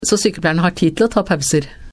Legg merke til hvordan dette sies .